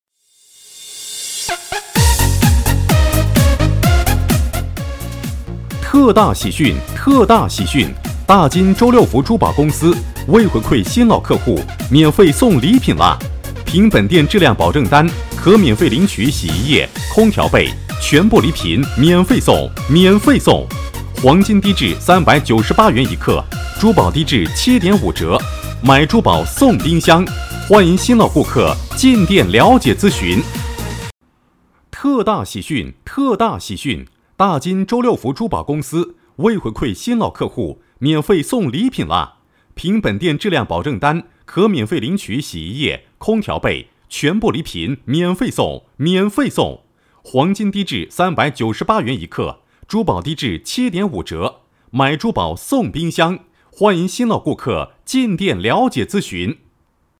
男32号配音师
7年从业经验，声音庄重、浑厚、大气。
代表作品 Nice voices 促销 广告 纪录片 朗诵 舌尖 新闻 专题片 促销-男32-电动车.mp3 复制链接 下载 促销-男32-珠宝店.mp3 复制链接 下载